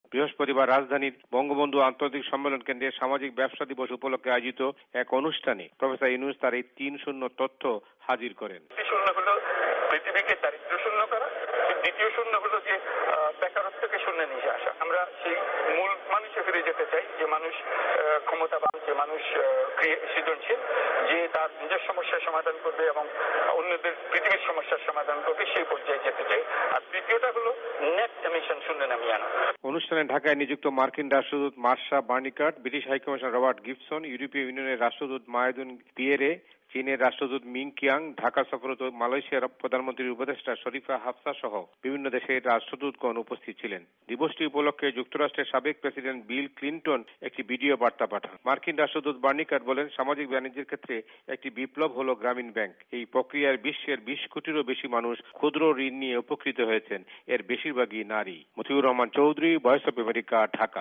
আজ সকালে রাজধানীর বঙ্গবন্ধু আন্তর্জাতিক সম্মেলন কেন্দ্রে ‘সোশ্যাল বিজনেস ডে’ উপলক্ষে এক সম্মেলনের উদ্বোধনী অনুষ্ঠানে তিনি কথা বলেন।